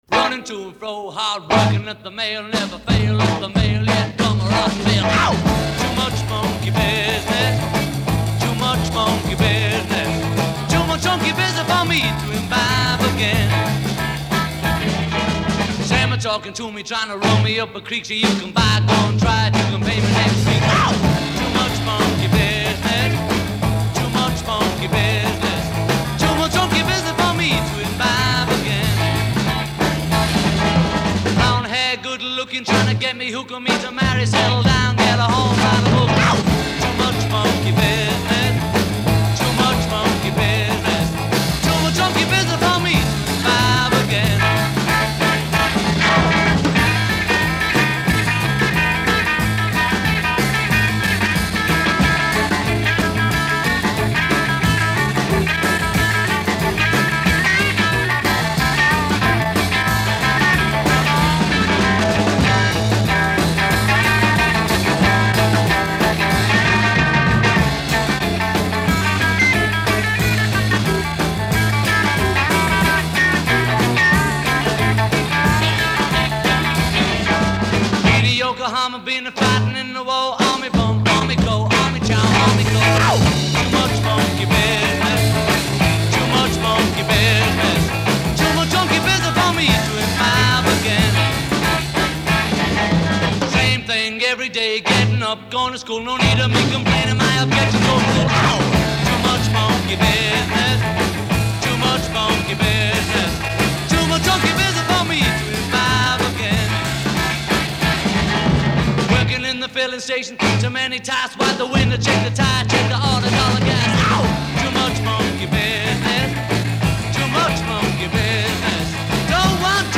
а записали на радио 4 раза в 1963-м